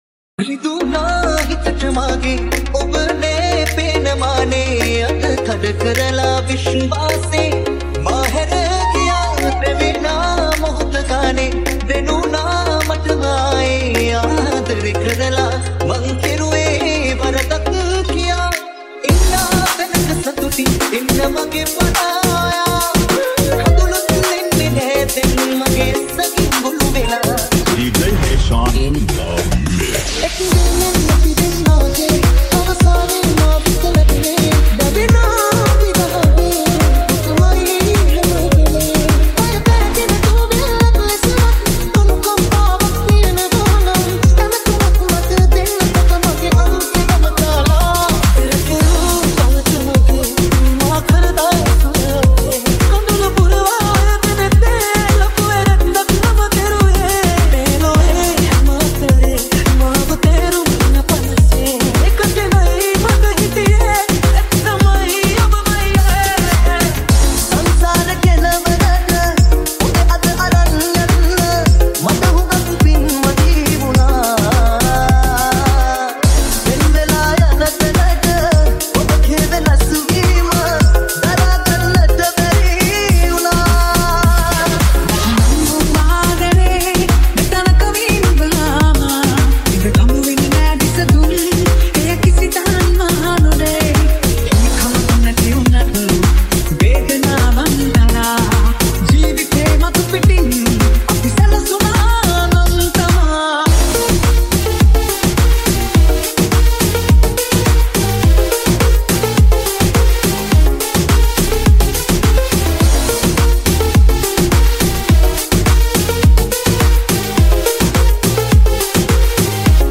High quality Sri Lankan remix MP3 (7).
remix